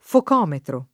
focometro [ fok 0 metro ]